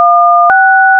Les sons que vous allez traiter sont des signaux de codes DTMF (Dual Tone Multiple Frequency) utilisés pour distinguer par le son les touches numérotées d'un clavier téléphonique (DTMF sur wikipedia).
L'appuie sur une touche génère un son composé de deux fréquences, selon le tableau suivant :